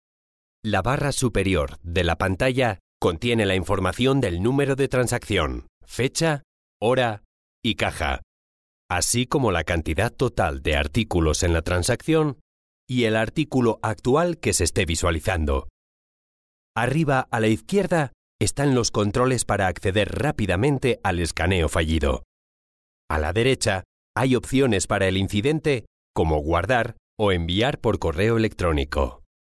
Trustworthy or irreverent personality, fresh & cool.
Sprechprobe: eLearning (Muttersprache):
★MY RIG★ -Mic. RODE NT1A -Preamp. Focusrite Scarlett Solo 2nd gen. -SONY MDR-7506 Headphones -DAW. Adobe Audition CC 2019 -Intel i5 / 16 RAM Gb